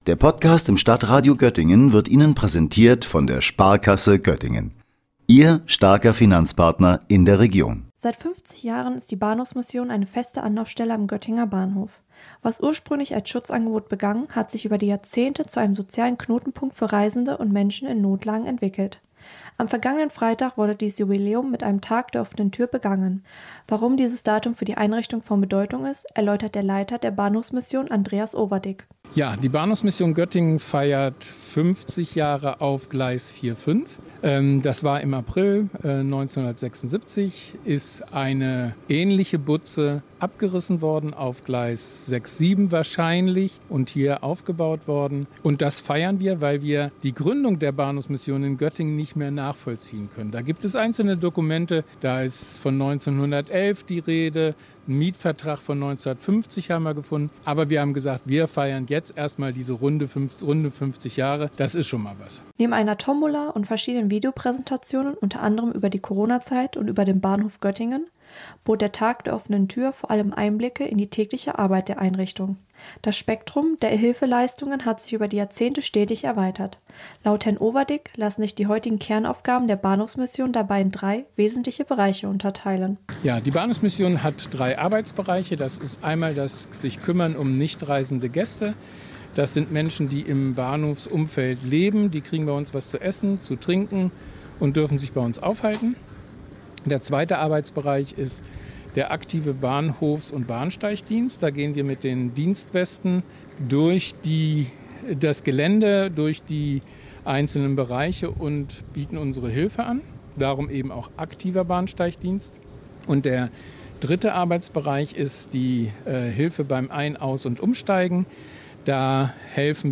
Doch abseits der Hektik an den Bahnsteigen gibt es seit Jahrzehnten eine Institution, die genau dort Halt gibt, wo er gebraucht wird: die Bahnhofsmission Göttingen. Anlässlich ihres 50-jährigen Bestehens in der aktuellen Trägerschaft öffnete die Einrichtung am vergangenen Freitag ihre Türen für Interessierte.